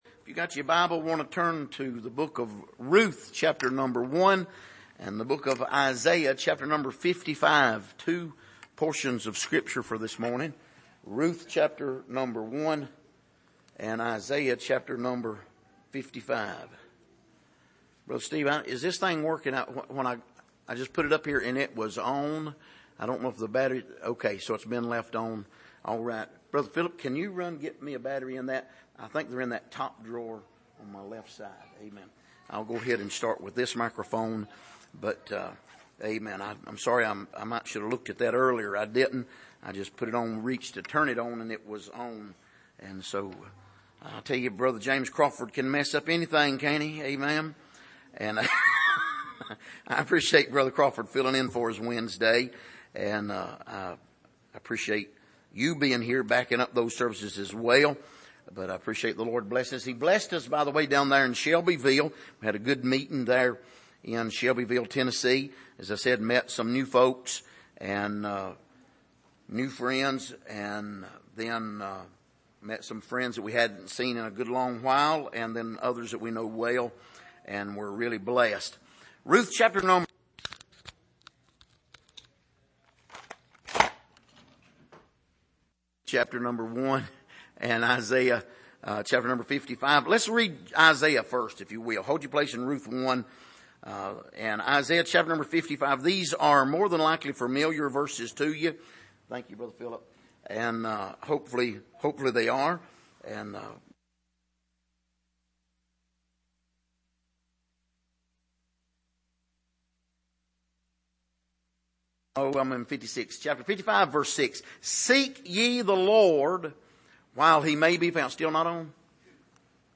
Passage: Isaiah 55:6-9, Ruth 1:19-22 Service: Sunday Morning